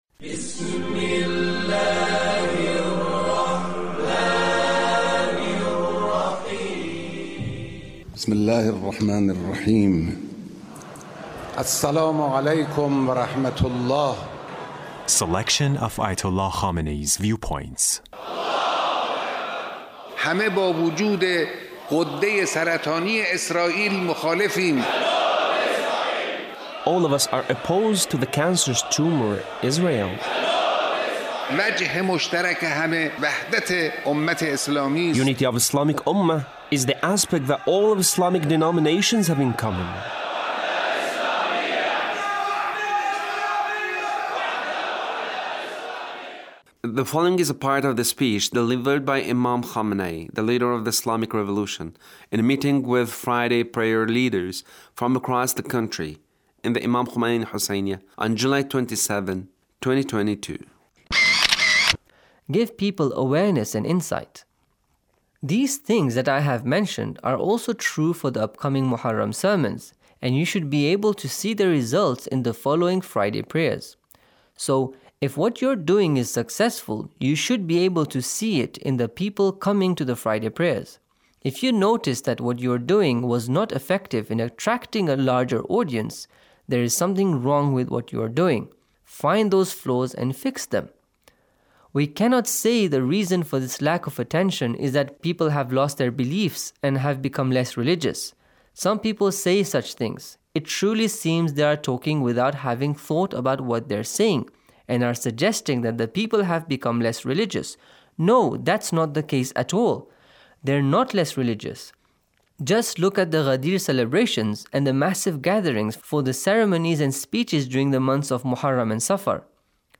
Leader's Speech on a Gathering with Friday Prayer Leaderst